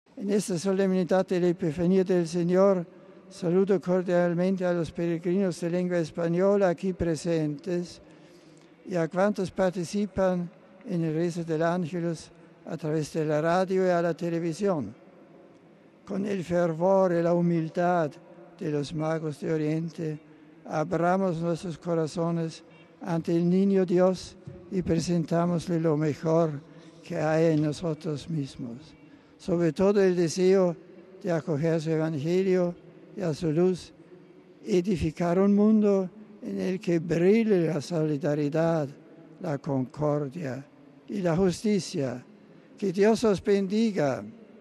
Saludos del Papa en español: